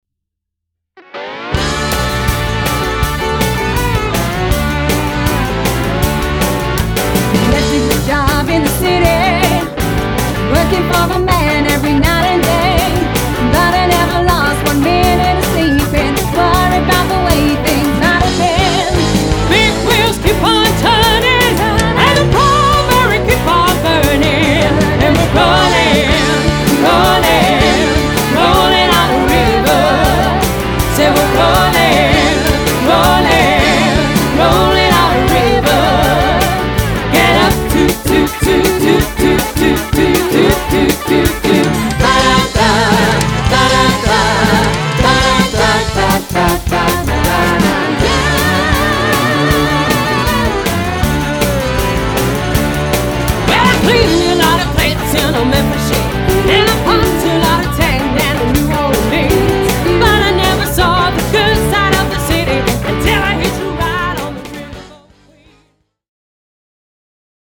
• Pop covers from the '60s through to the present day
• Flexible line-up though usually a six-piece band
• Energetic wedding reception party performances
consists of keyboards, drums, guitar, bass and two vocalists